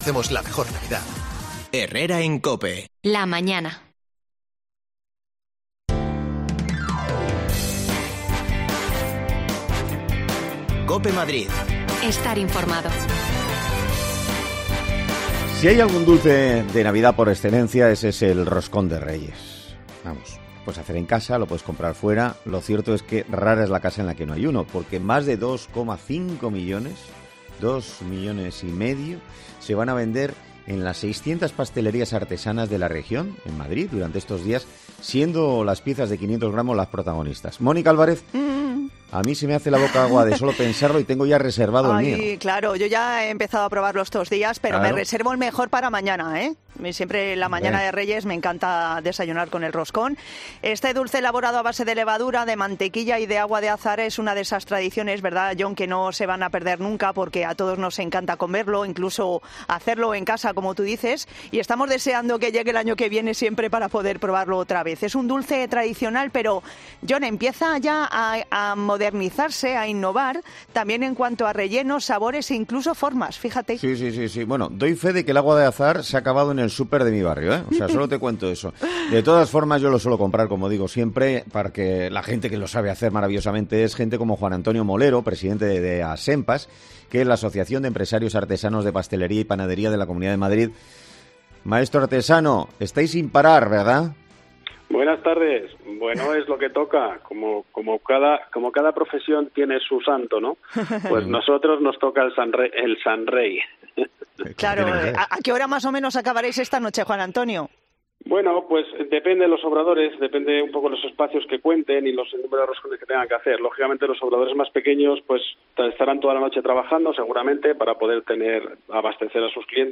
Hablamos con un maestro...